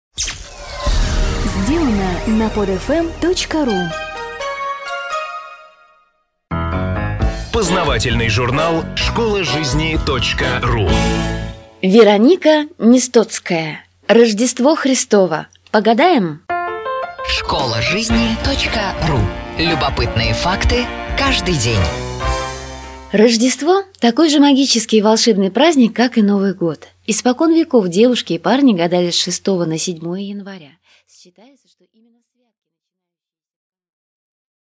Аудиокнига Как гадают под Рождество?